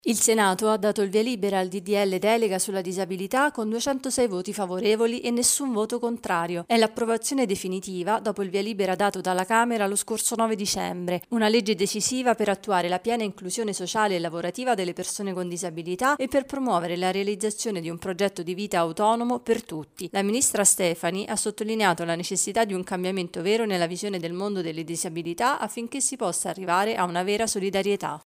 servizio_disabilita.mp3